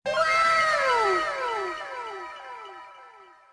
Wooow.191ae0ed0254f4f96f29.mp3